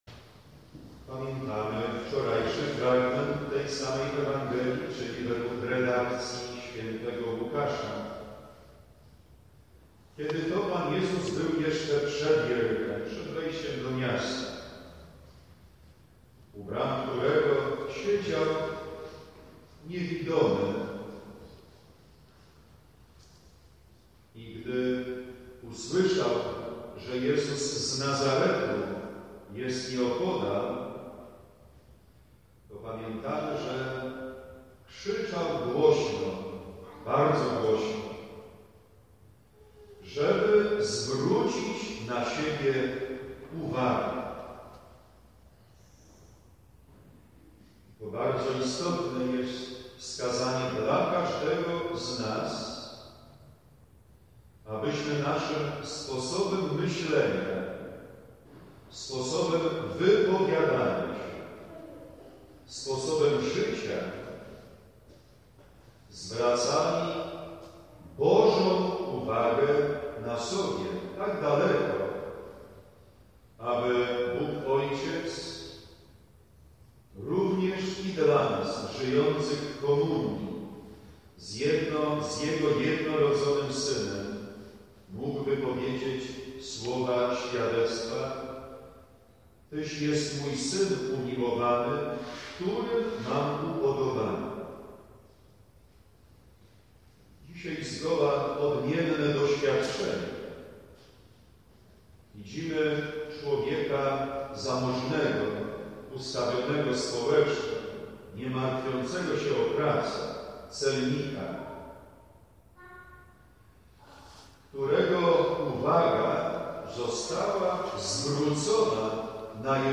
15 listopada 2016 – Msza św. – homilia